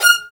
Index of /90_sSampleCDs/Roland L-CD702/VOL-1/STR_Symphonic/STR_Symph.+attak
STR ATTACK19.wav